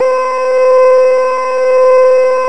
机器人声乐 " 合成歌声音符C 变奏9
Tag: 歌唱 语音 合成器 数字 语音编码器 请注意 puppycat 机器人 电子 合成 自动调谐 C